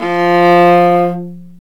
Index of /90_sSampleCDs/Roland - String Master Series/STR_Viola Solo/STR_Vla3 Arco nv
STR VIOLA 03.wav